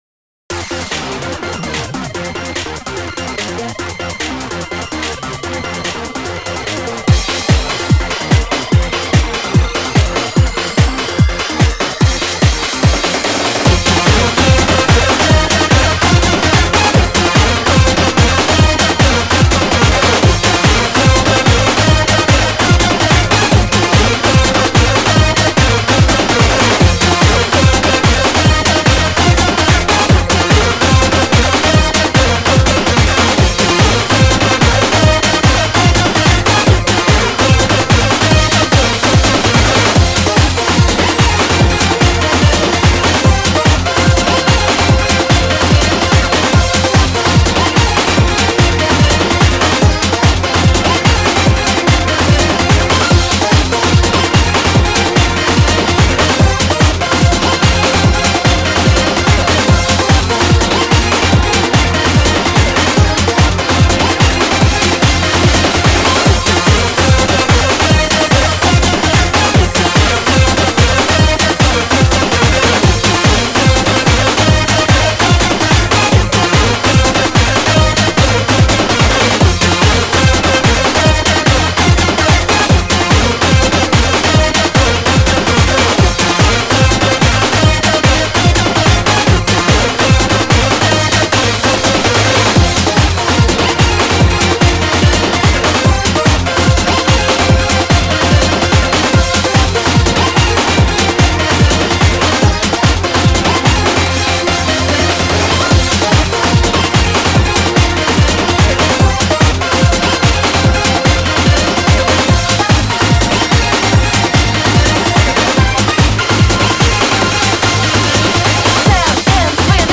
• 曲のスタイル: テクノ／ブレイクビーツ
• BPM: 146